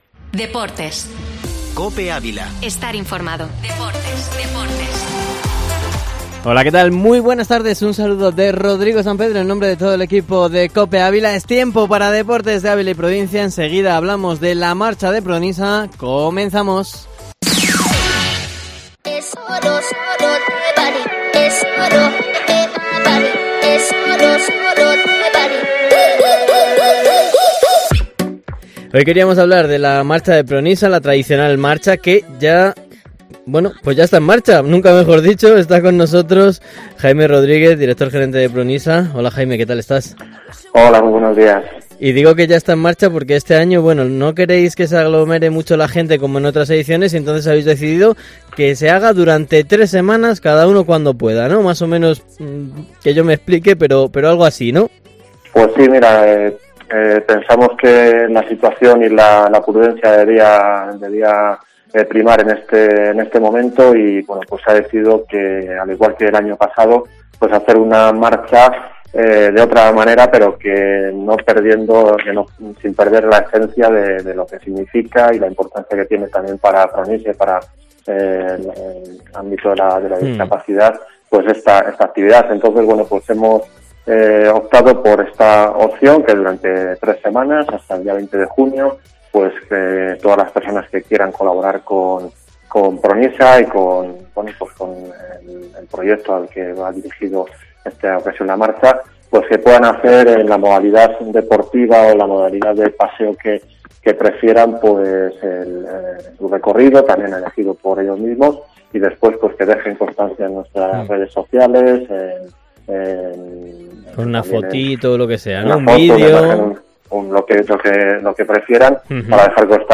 COPE Ávila Entrevista